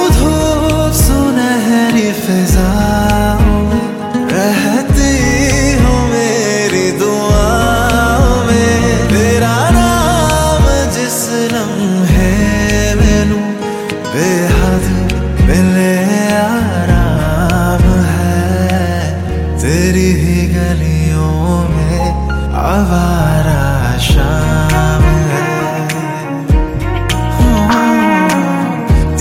Set this emotional Hindi love tune as your ringtone.